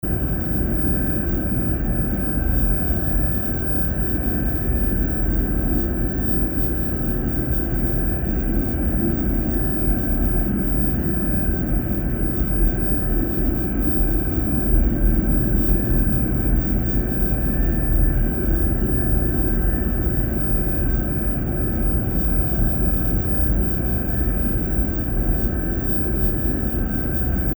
Gemafreie Sounds: Flugzeug - Verkehrsflugzeug
mf_SE-671-noisy_mid_range_atmo.mp3